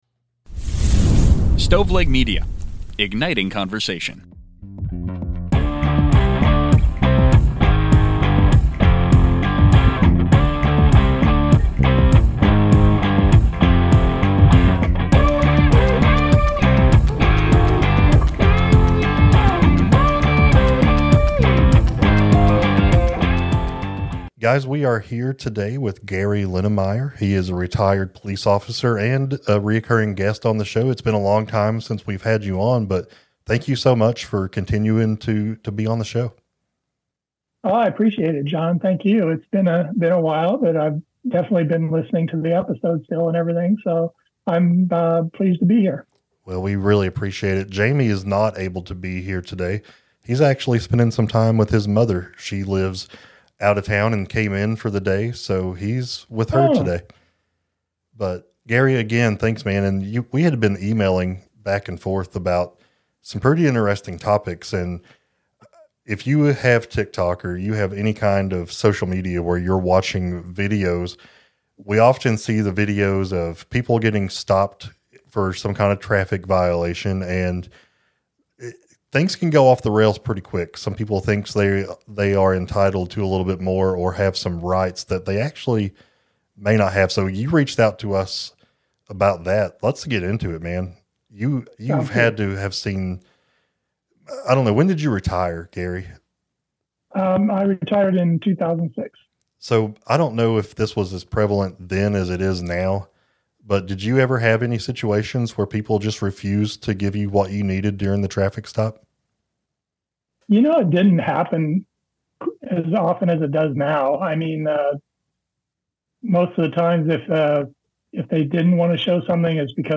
True Crime, Society & Culture, Documentary, Personal Journals